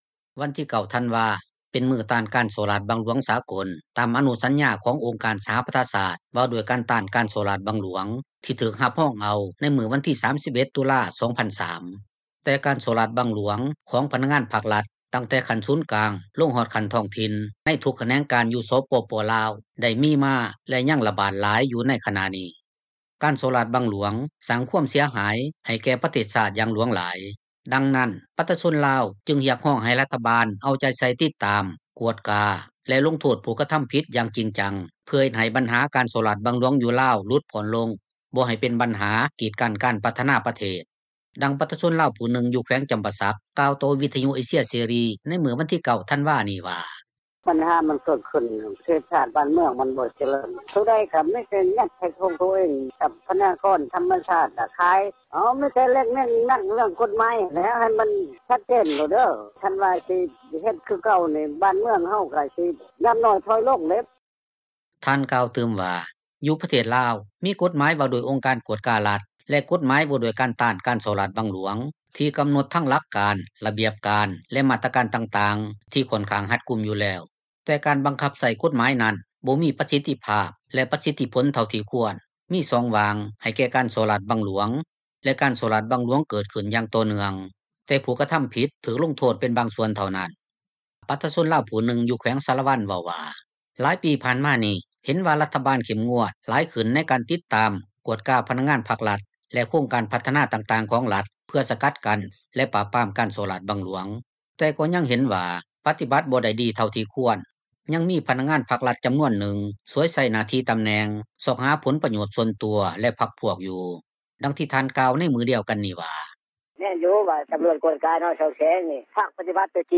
ດັ່ງປະຊາຊົນລາວຜູ້ນຶ່ງ ຢູ່ແຂວງຈໍາປາສັກ ກ່າວຕໍ່ວິທຍຸ ເອເຊັຽ ເສຣີ ໃນມື້ວັນທີ 9 ທັນວານີ້ວ່າ: